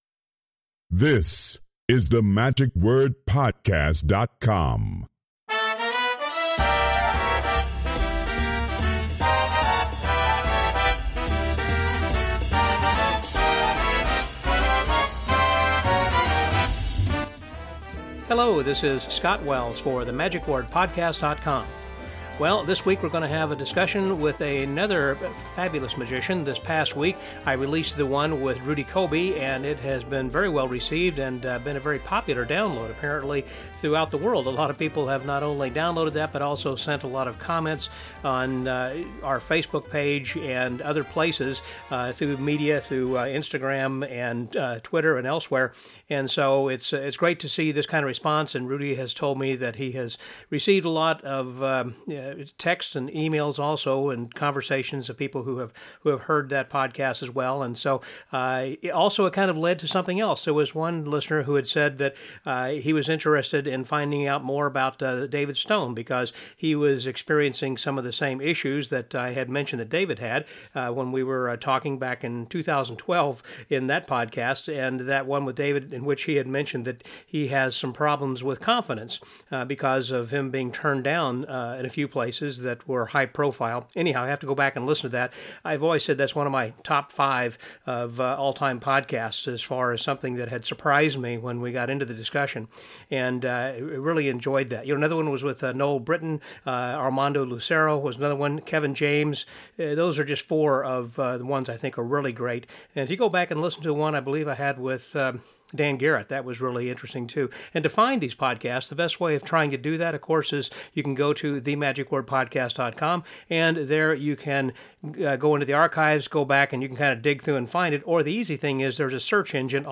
Following a recent screening of "Dealt", a documentary on the life of legendary card mechanic/magician Richard Turner, we conducted a Question and Answer session via Skype over the big screen in the theater.